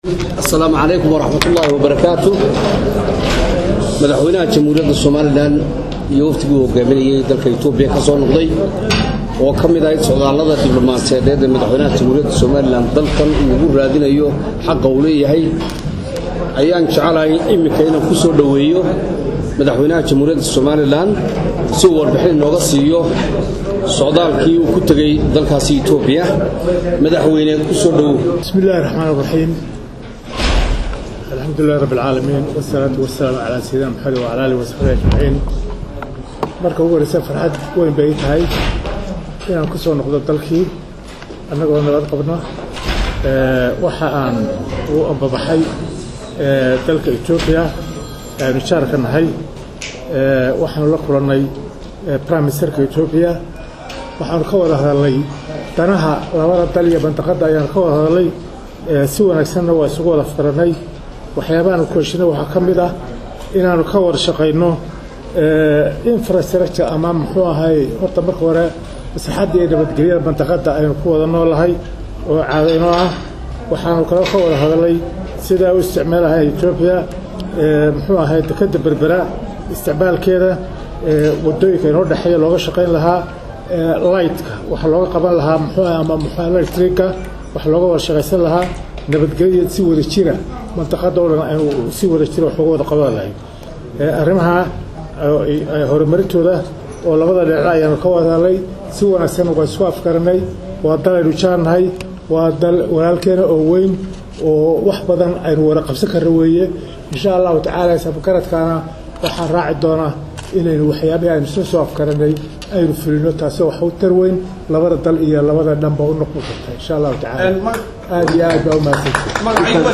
Madaxweynaha iyo Weftigiisa Waxaa ku Soo dhaweeyay Madaarka Cigaal Madaxweyne ku Xigeenka Somaliland Waxaanu Madaxweyne Axmed Maxamed Maxamud Siilaanyo Warbaahinta ugu Waramay Qolka Nasashada ee Madaarka isagoo ka Warbixiyay Kulankii uu la Yeeshay Ra’isal Wasaaraha Itoobiya .